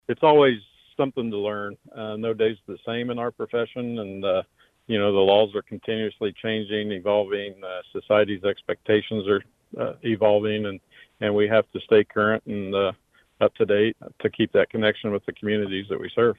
Once again, the Lyon County Sheriff’s Office’s annual statewide spring training seminar was at capacity, according to Lyon County Sheriff Jeff Cope in an interview with KVOE News Friday.